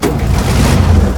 tank-engine-start-2.ogg